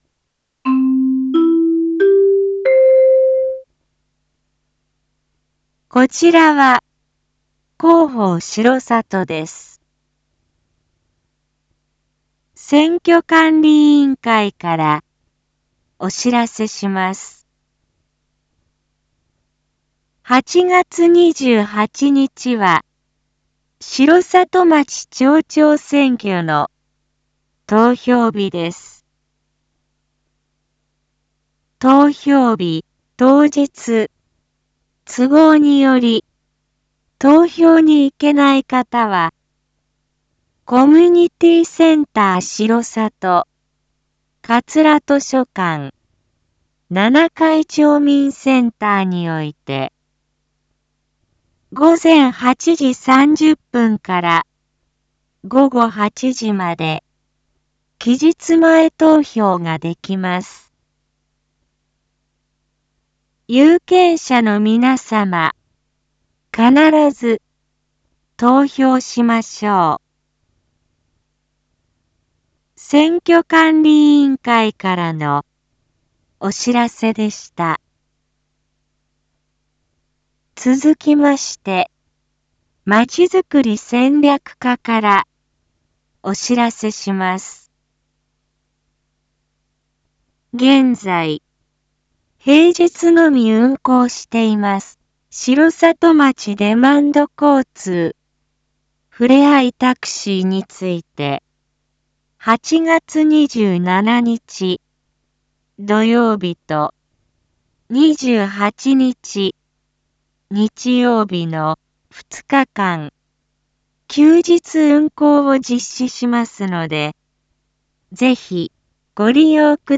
一般放送情報
Back Home 一般放送情報 音声放送 再生 一般放送情報 登録日時：2022-08-24 19:02:43 タイトル：R4.8.24 19時放送分 インフォメーション：こちらは広報しろさとです。